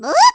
Relive the classic Mario Kart: Double Dash!! with some of these sounds from the game.
Maro Kart Double Dash Sound Effects